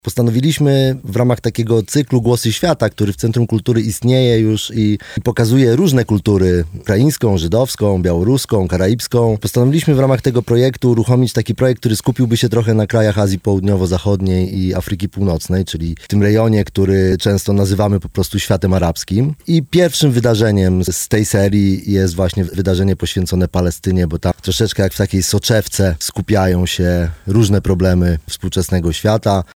[PORANNA ROZMOWA] Tu i teraz dążmy do międzynarodowego dialogu